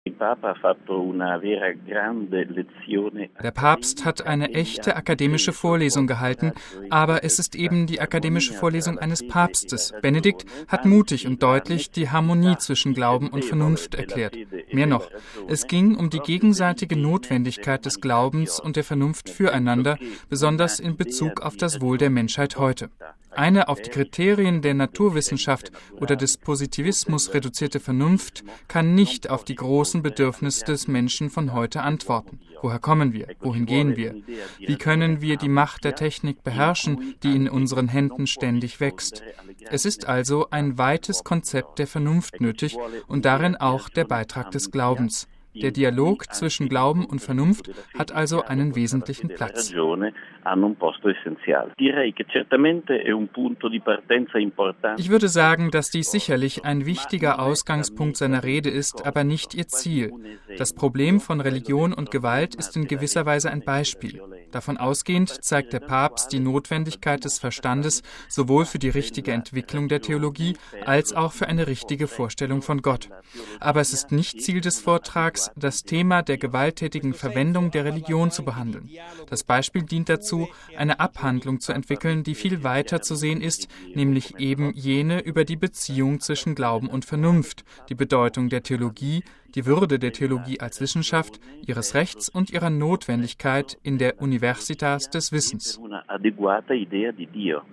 MP3 Glaube und Vernunft: Die Rede Papst Benedikts gestern Abend an der Universität Regensburg war eine intellektuell stark verdichtete theologische Vorlesung. Wir fragten P. Federico Lombardi SJ, Direktor des Vatikanischen Pressesaals und von Radio Vatikan, nach den Hauptpunkten.